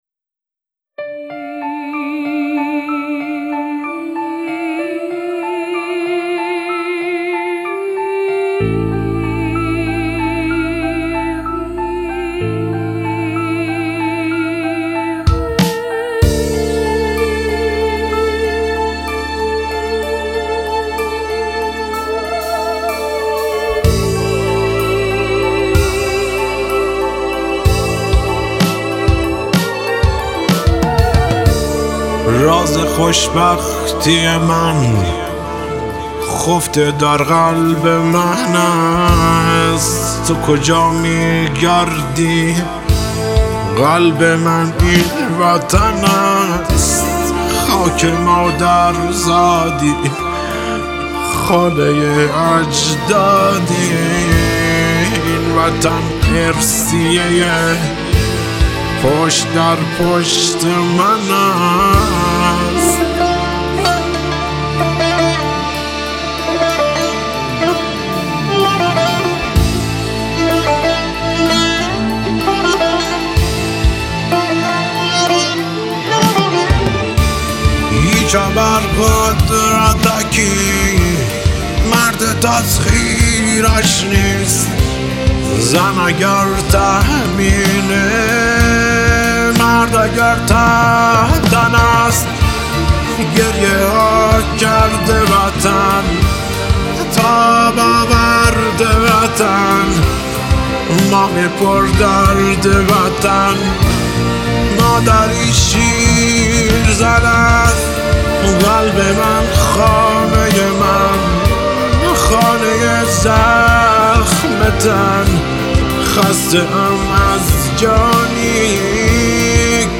قطعه کوتاه موسیقی